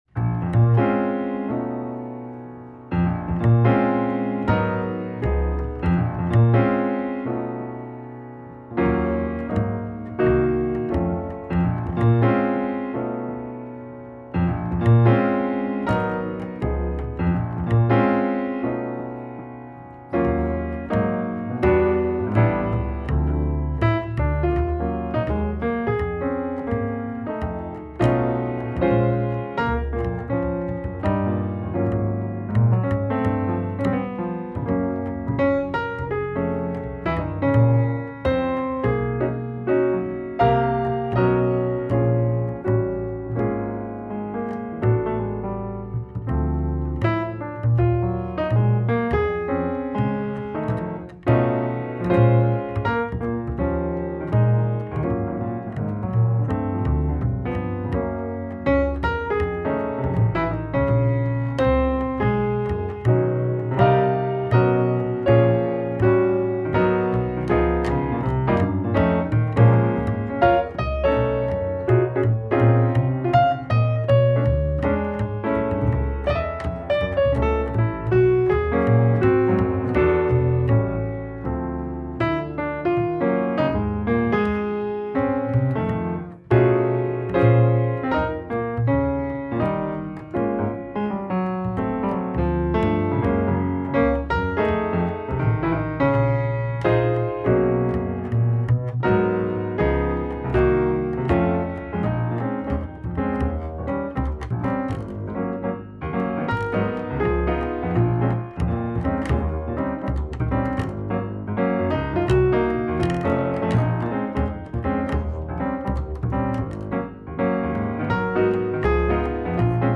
The live recording came together somewhat fortuitously.